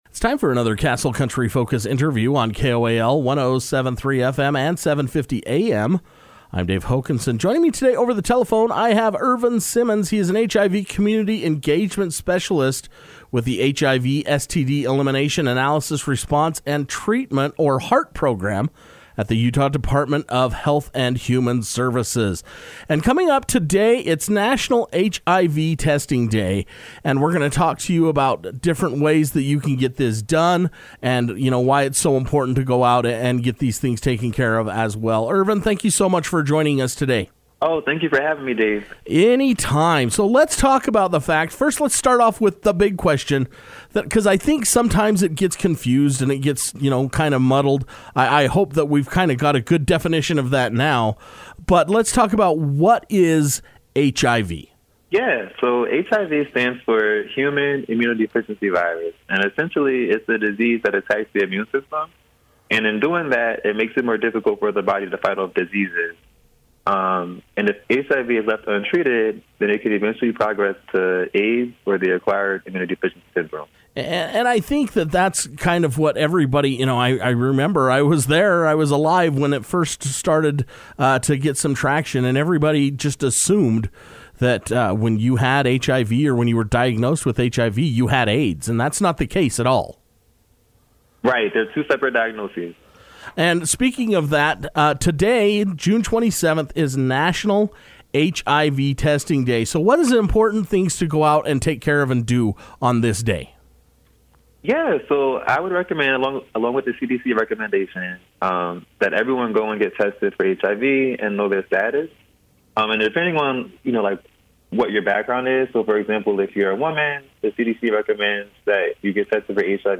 The National HIV Testing Day is recognized on June 27 and Castle Country Radio was able to speak over the telephone with HIV Community Engagement Specialist